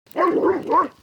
Dog Barking 01 Sound Button - Free Download & Play
Dog Barking Sound380 views